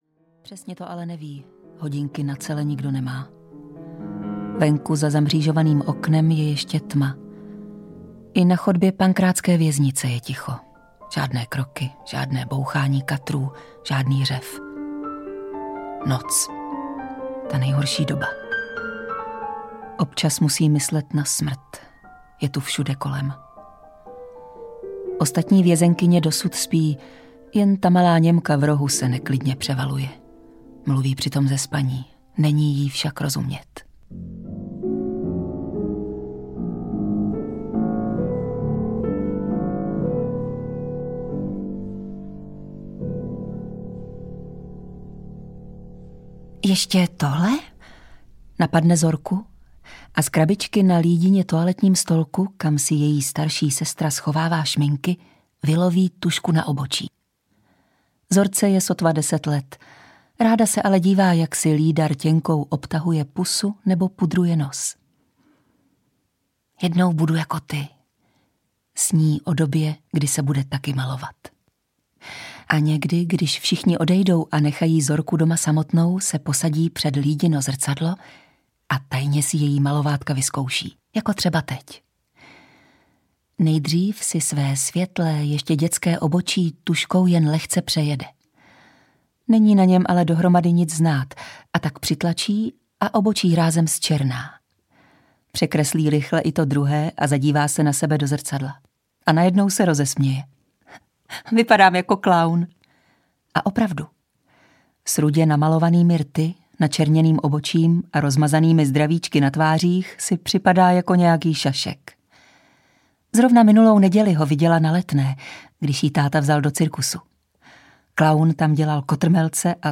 Sestry B. audiokniha
Ukázka z knihy